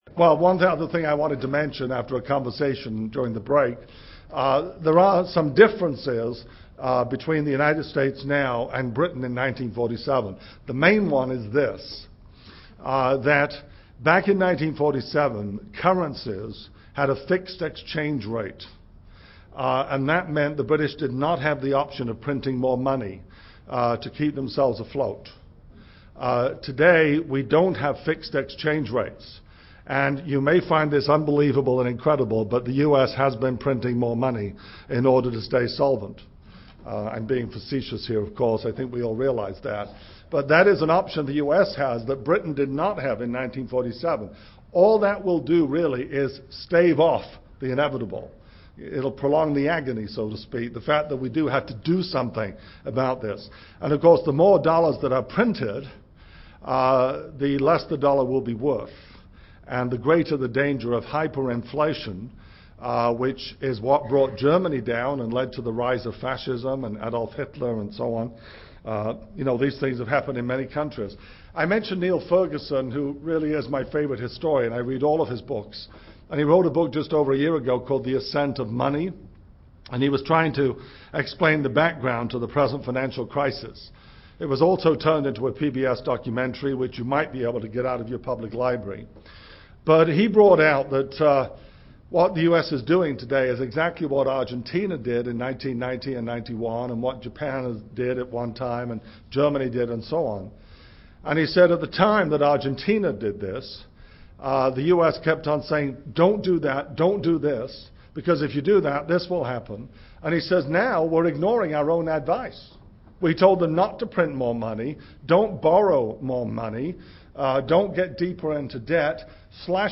World News and Prophecy Seminar Message
Given in Seattle, WA